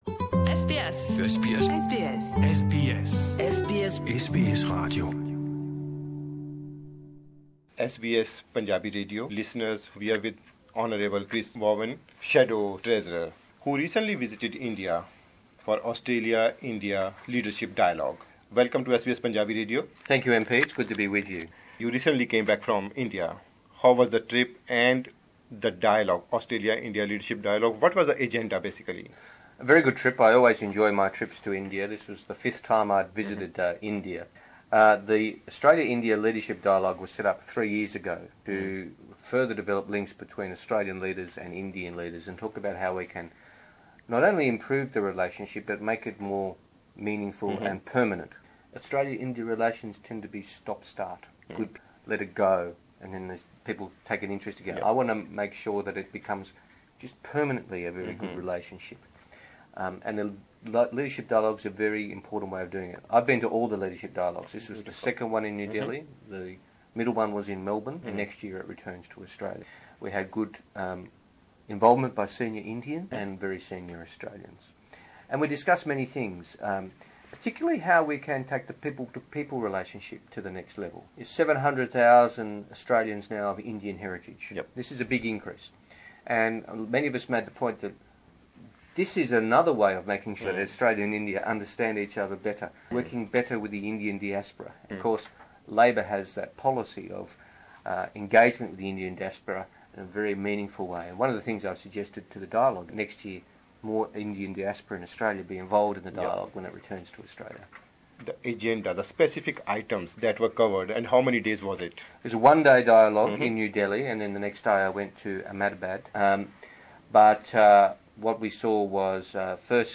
In an exclusive interview with SBS Punjabi, Shadow Treasurer Chris Bowen restated Labor's stand on the federal government's proposed citizenship changes.
Shadow treasurer talking to SBS Punjabi Source: MPS